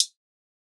Closed Hats
nightcrawler hat.wav